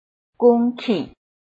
臺灣客語拼音學習網-客語聽讀拼-詔安腔-鼻尾韻
拼音查詢：【詔安腔】gung ~請點選不同聲調拼音聽聽看!(例字漢字部分屬參考性質)